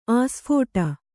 ♪ āsphōṭa